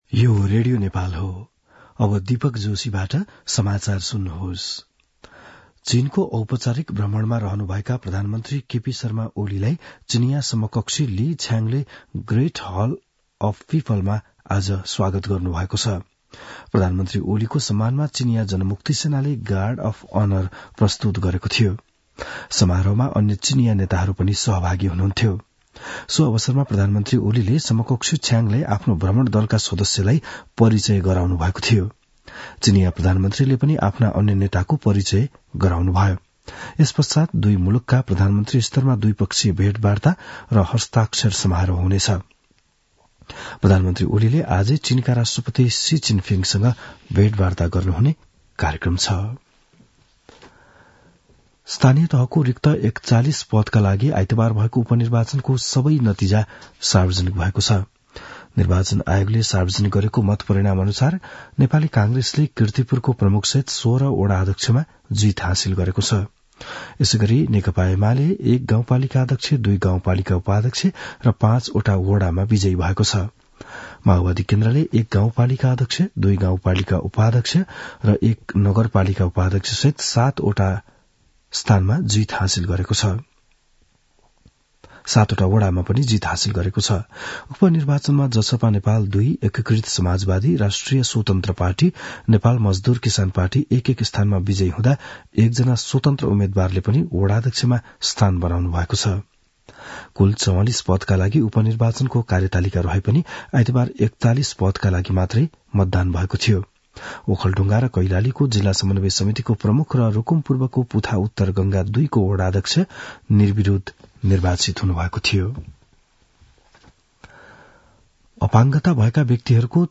बिहान ११ बजेको नेपाली समाचार : १९ मंसिर , २०८१
11-am-nepali-news-1-2.mp3